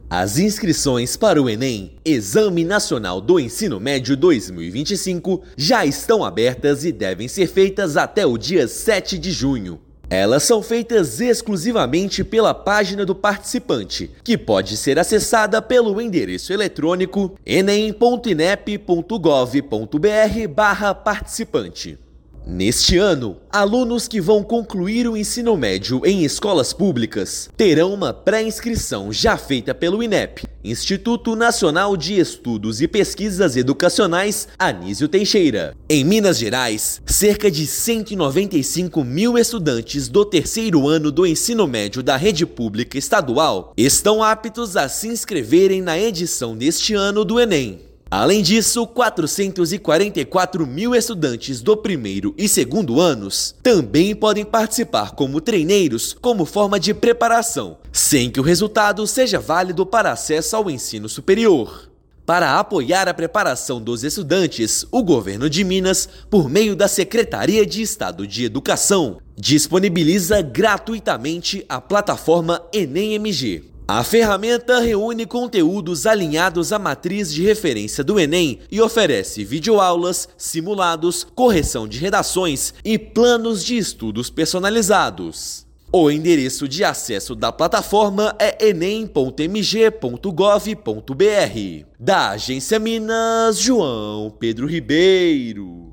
Cerca de 195 mil estudantes concluintes da rede estadual devem confirmar a participação no exame; Governo de Minas oferece preparação gratuita por meio da plataforma Enem MG. Ouça matéria de rádio.